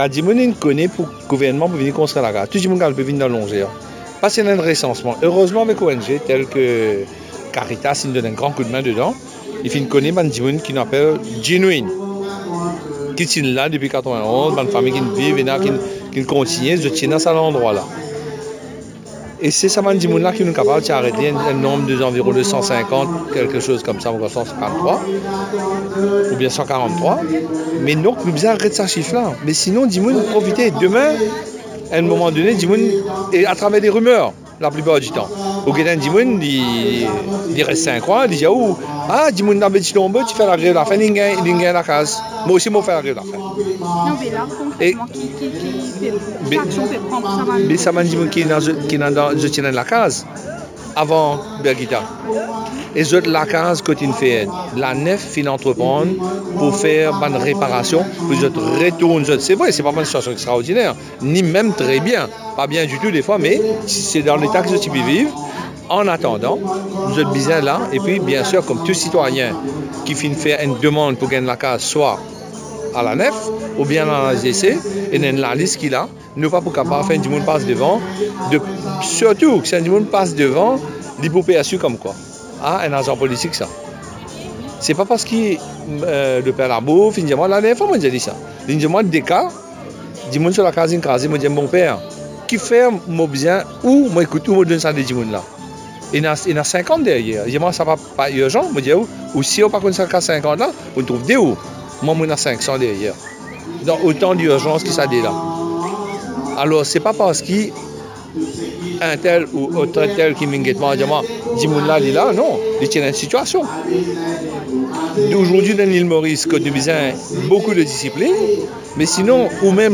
Pour le ministre de l’Intégration sociale, ce sont des familles «indisciplinées» qui y prennent racine dans une tentative de forcer la main des autorités. Il intervenait ce mercredi 6 juin, à l’issue du lancement de projets sociaux au centre Oui Nou Kapav, aux Longères de Baie-du-Tombeau.